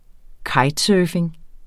Udtale [ ˈkɑjd- ]